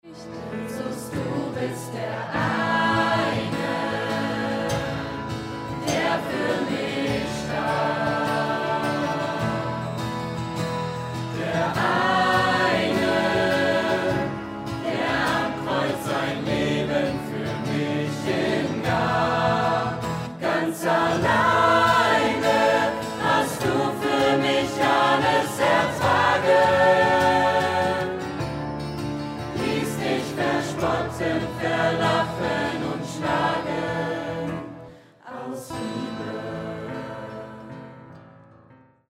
Notation: SATB
Tonart: Am, A, Hm
Taktart: 6/8
Tempo: 172 bpm
Parts: 3 Verse, Refrain, 2 Codas
Noten, Noten (Chorsatz)